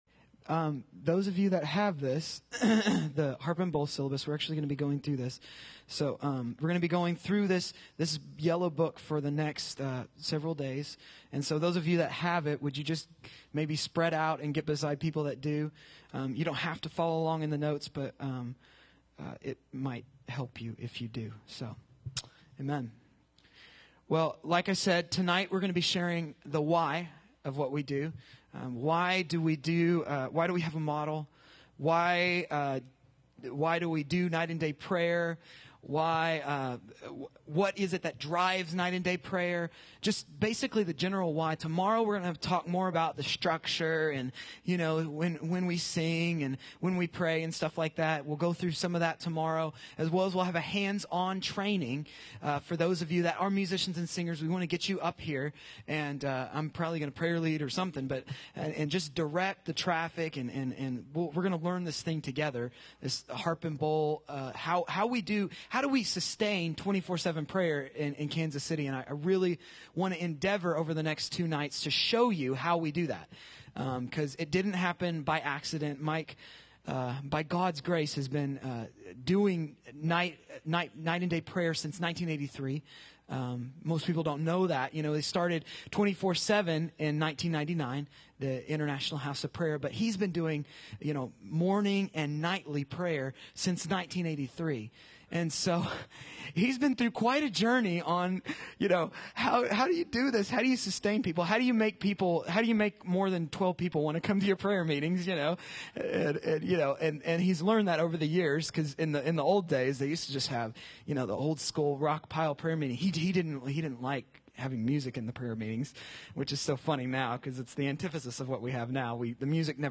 Living Grace City Church, Clarksburg, WV